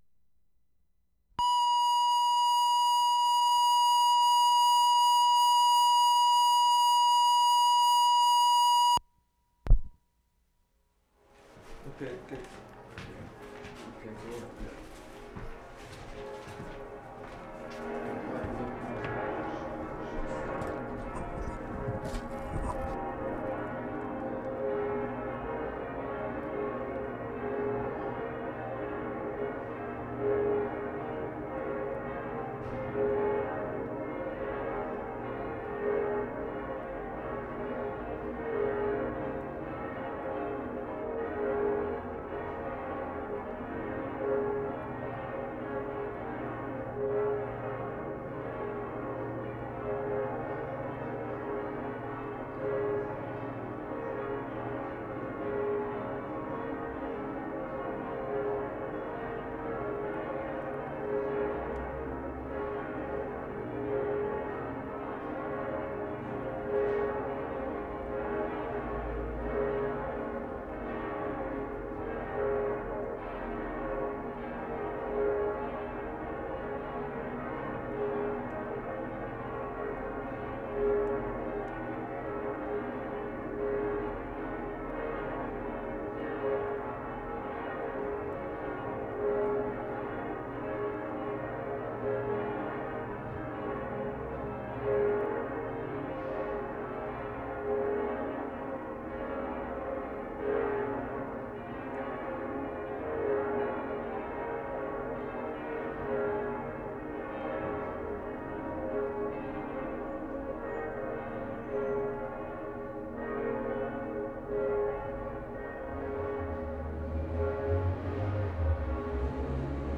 WORLD SOUNDSCAPE PROJECT TAPE LIBRARY
Trento, Italy March 26/75
CHURCH BELLS (cathedral) from hotel window.
1. Early morning bells, fair distance away, but little interference from town (2 cars passing).